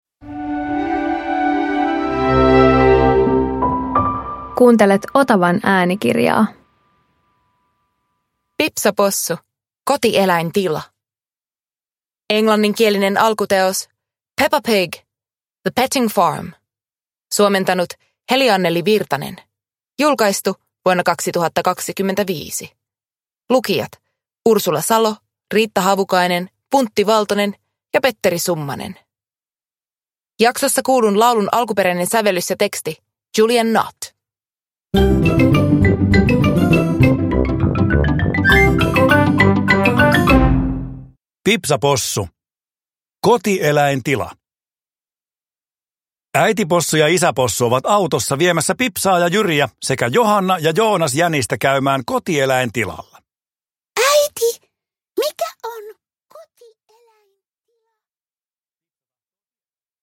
Pipsa Possu - Kotieläintila – Ljudbok